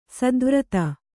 ♪ sadvrata